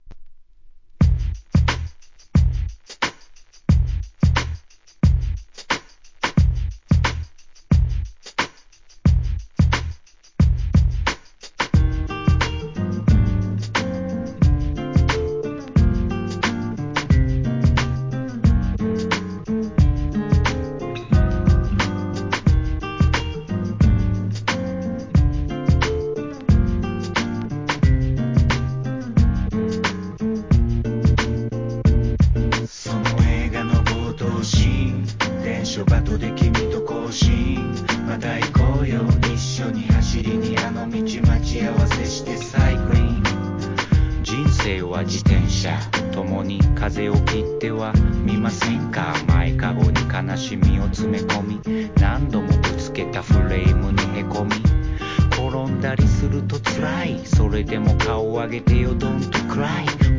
JAPANESE HIP HOP/R&B